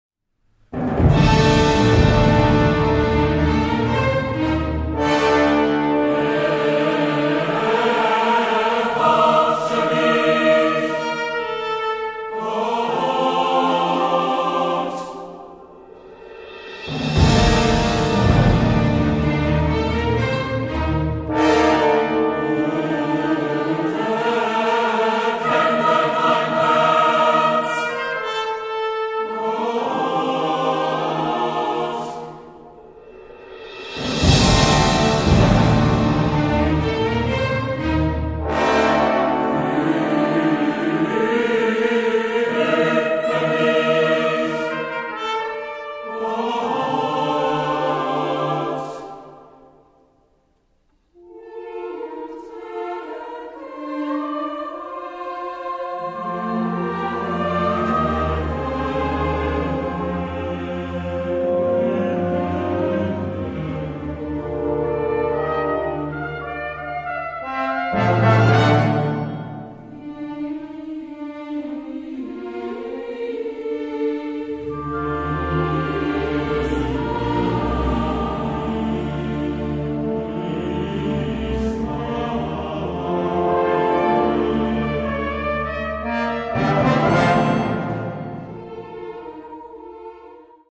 05.2001 / UA 04.06.2001, Wiesbaden, Neuapostolische Kirche